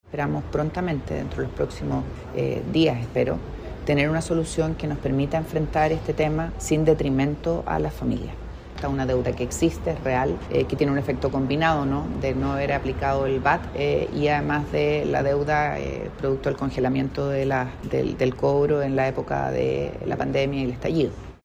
La ministra de Energía, Ximena Rincón, afirmó que el Ejecutivo está revisando los antecedentes para diseñar una solución que no encarezca las cuentas de las familias, y recalcó que la deuda es real y no puede seguir postergándose.